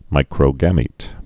(mīkrō-gămēt, -gə-mēt)